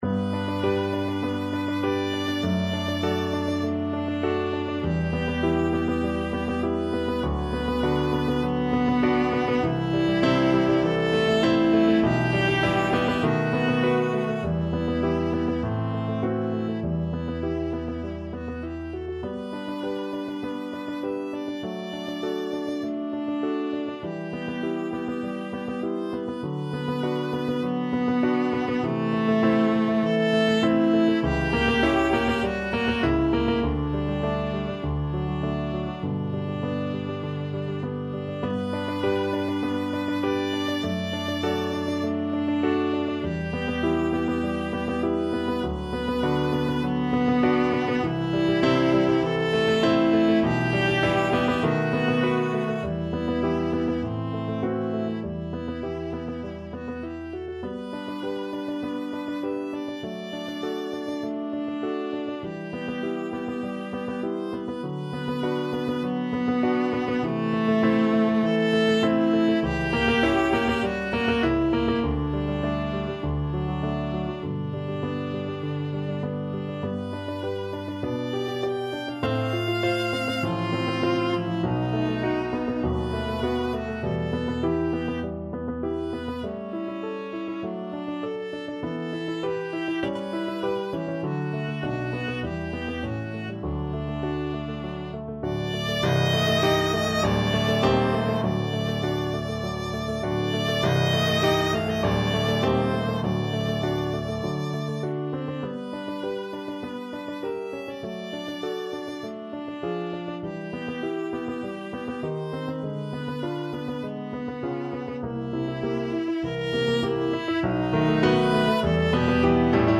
Allegro moderato (View more music marked Allegro)
4/4 (View more 4/4 Music)
Classical (View more Classical Viola Music)